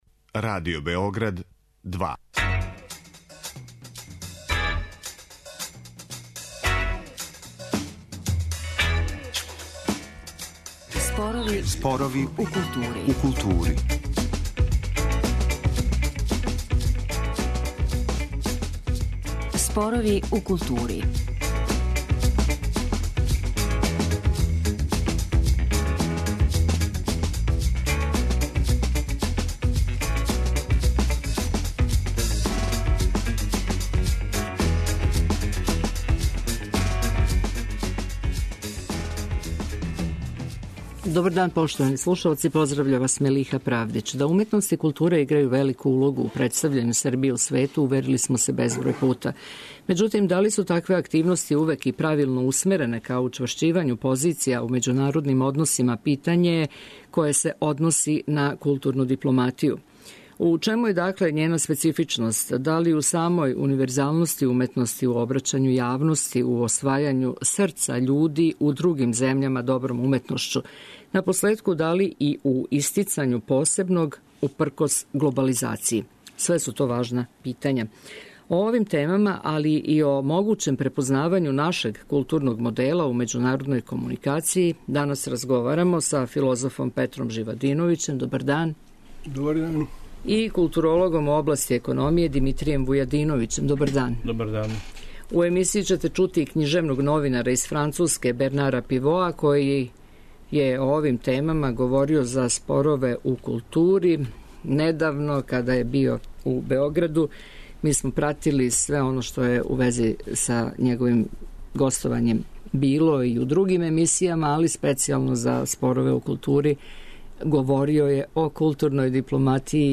У емисији ћете чути и књижевног новинара из Француске Бернара Пивоа, који је о овим темама говорио за Спорове у култури.